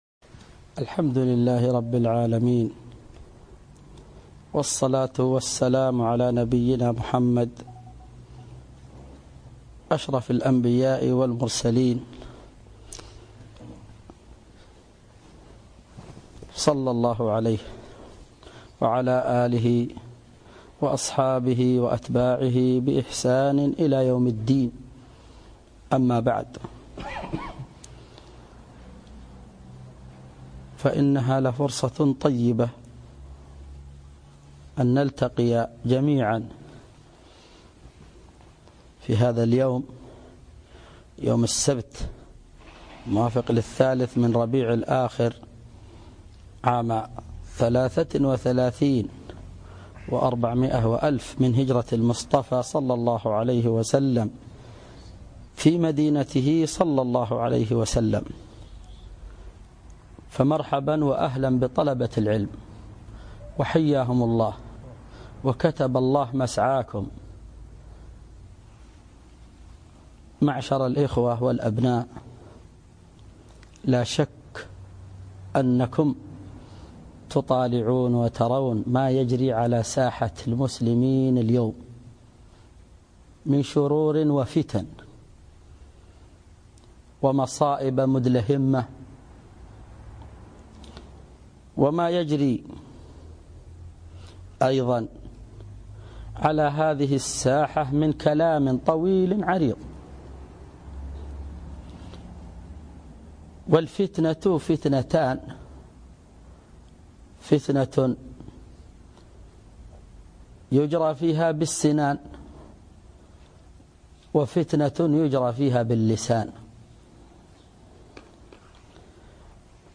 لقاء